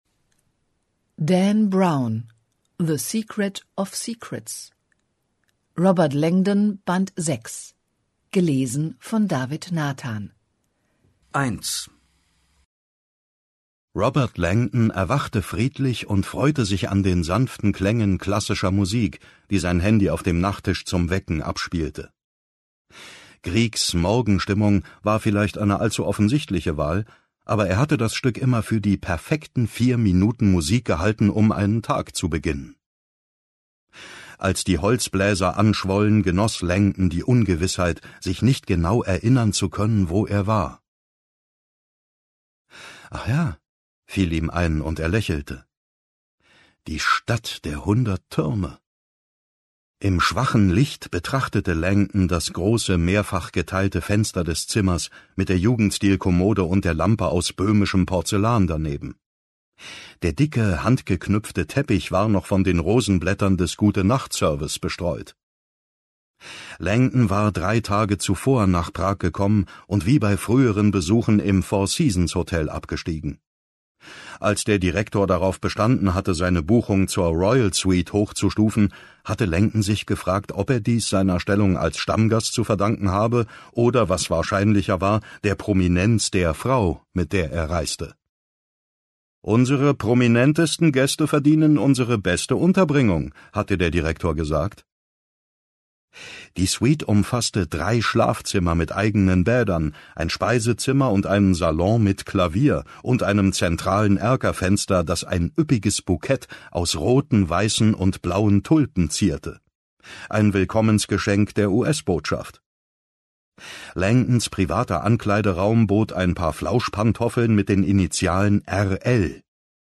David Nathan liest die neuen Thriller um Symbolforscher Robert Langdon: